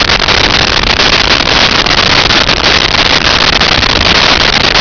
Sfx Amb Avalance Loop
sfx_amb_avalance_loop.wav